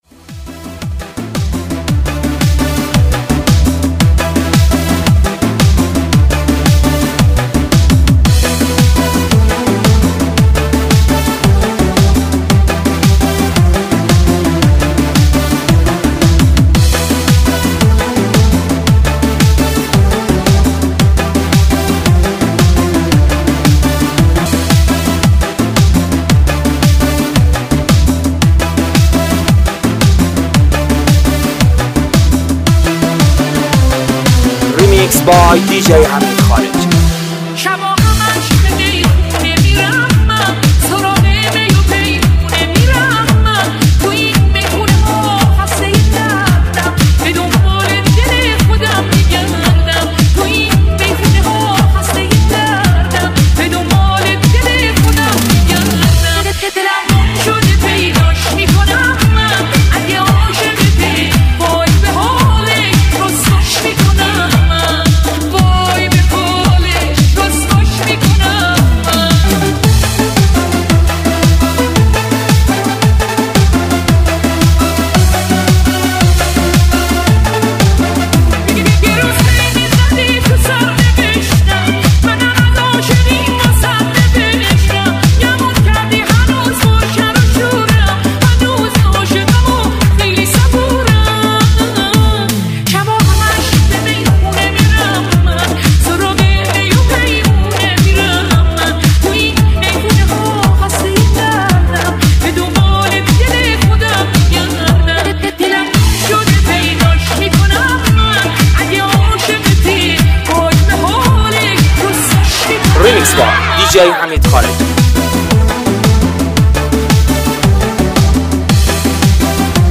دانلود ریمیکس پرانرژی